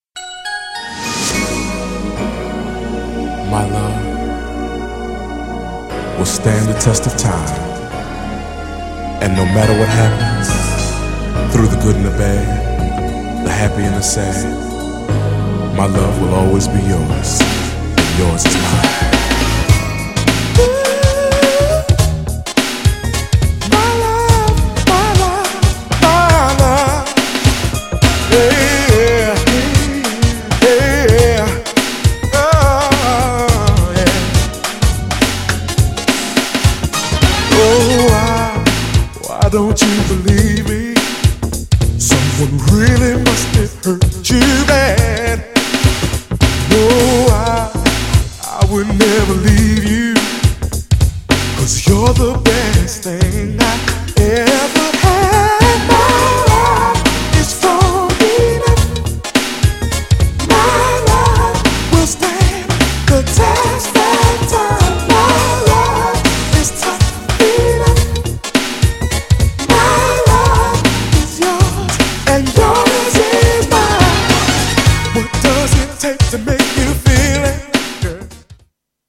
ダンサブル＆ソウルフルなNEW JACK SWING!! そして注目はJAZZYなINSTのピアノバージョン!!!
GENRE R&B
BPM 106〜110BPM
ピアノが美しい # 男性VOCAL_R&B